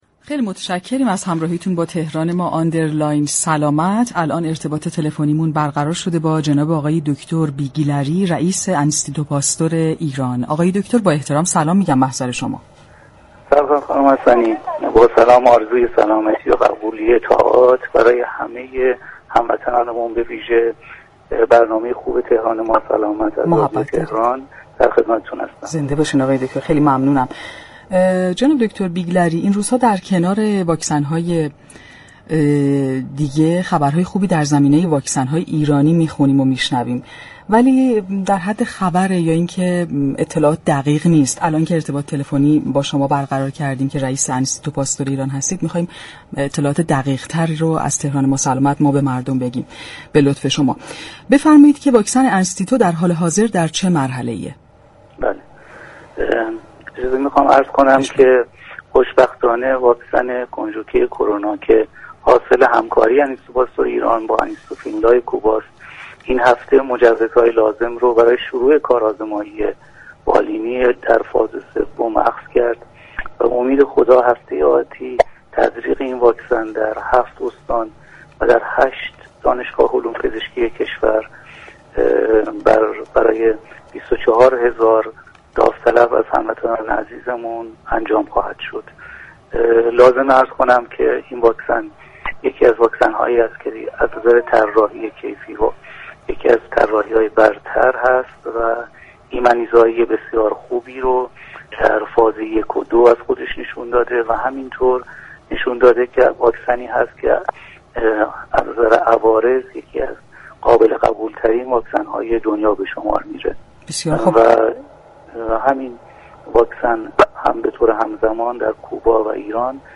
علیرضا بیگلری رئیس انستیتو پاستور ایران در گفتگو با برنامه «تهران ما سلامت» رادیو تهران